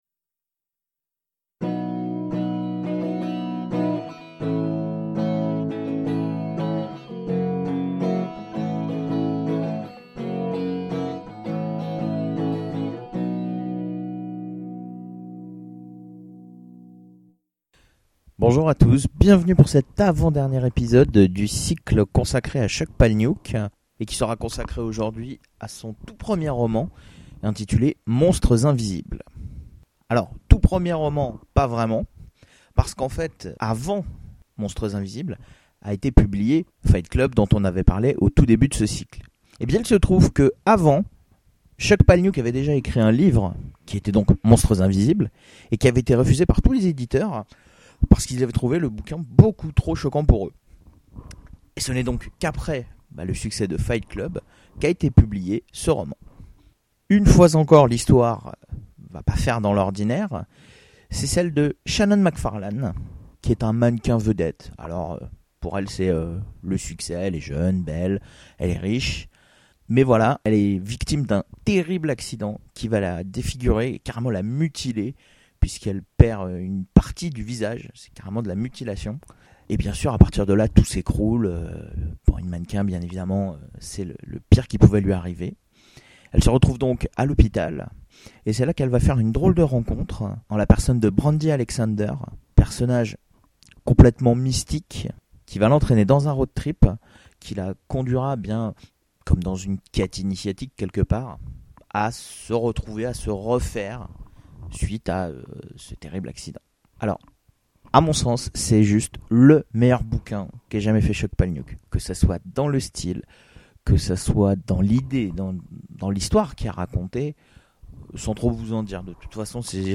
Session de lecture : Cycle Chuck Palahniuk (4)